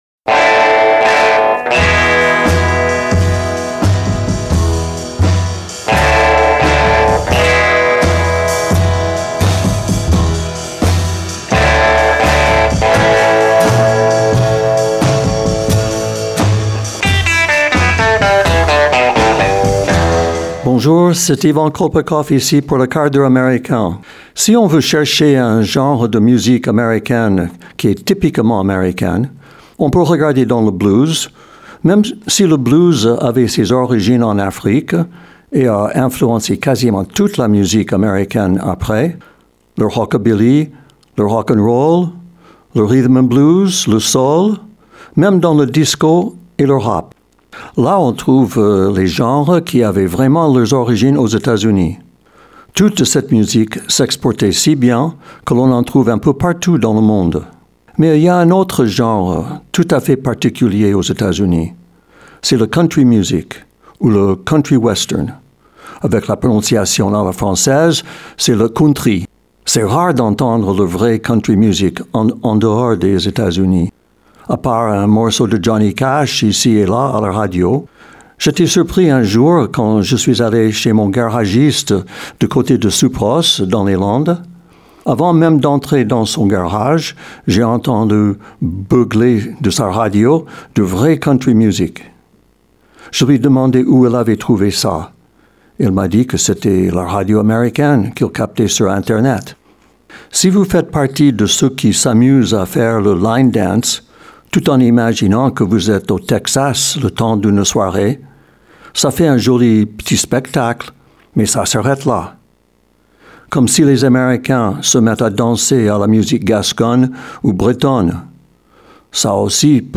Le 1/4 D’HEURE AMÉRICAIN #106 – COUNTRY MUSIC – Radio MDM